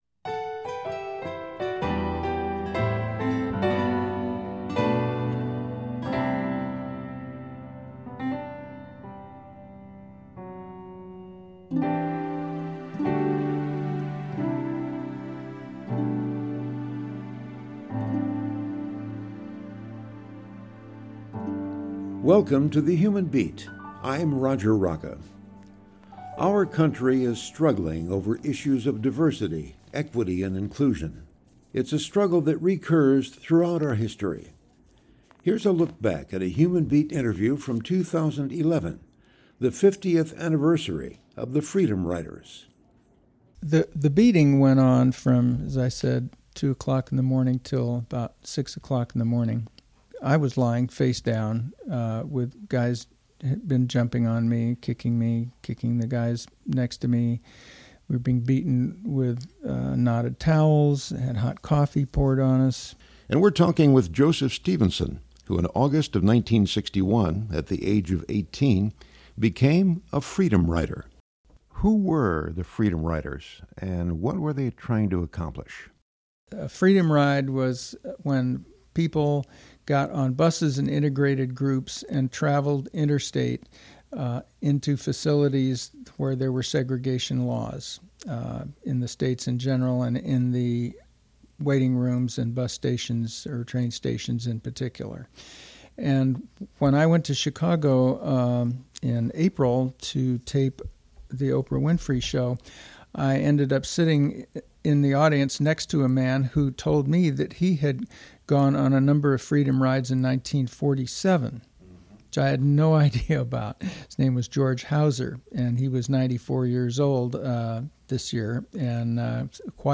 Here’s a look back at a Human Beat interview from 2011, the 50th anniversary of the Freedom Riders.